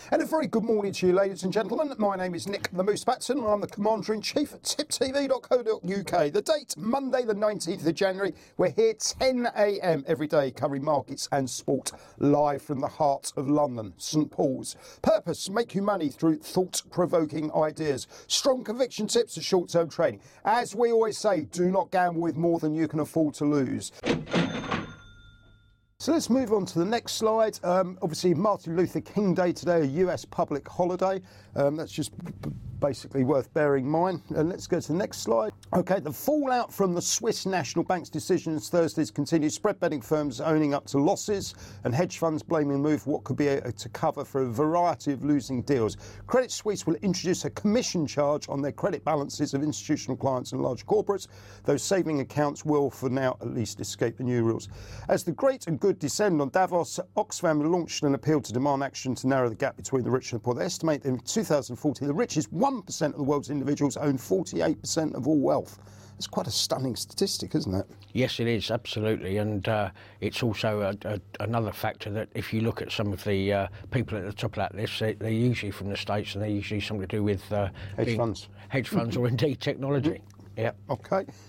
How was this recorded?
Live Market Round-Up & Soapbox thoughts